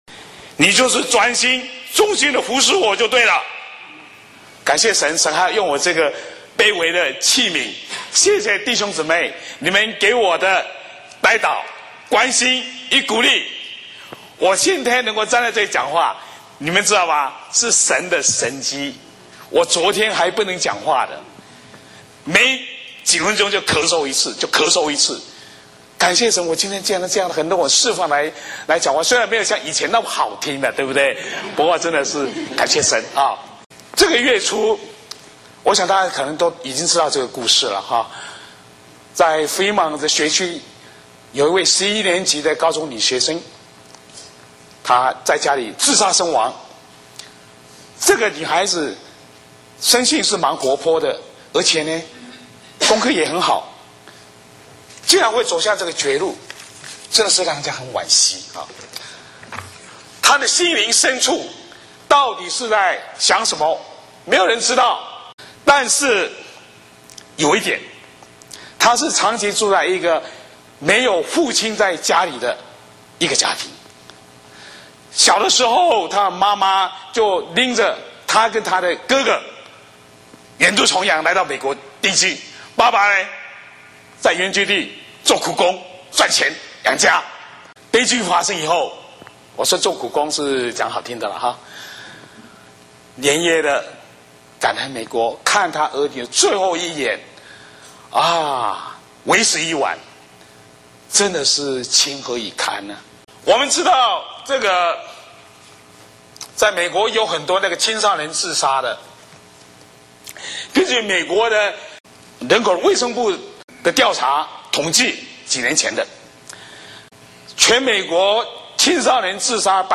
主日信息《做个无愧的父亲